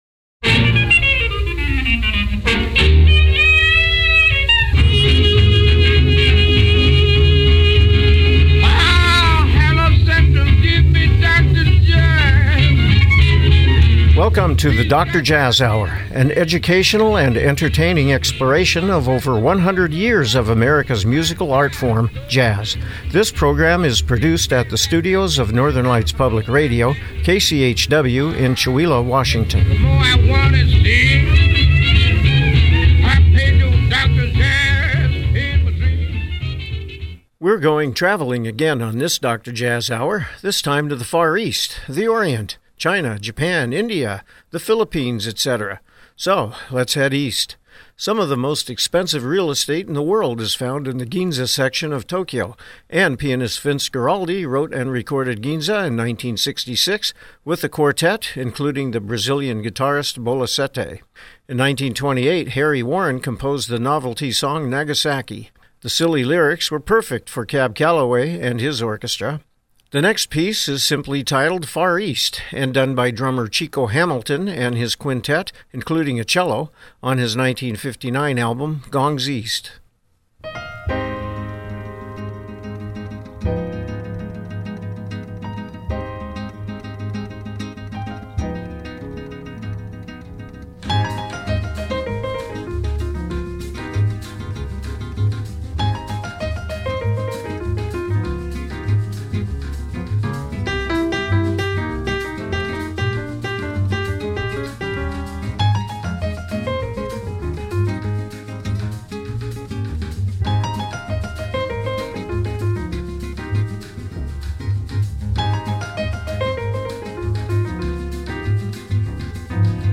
Program Type: Music